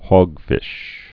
(hôgfĭsh, hŏg-)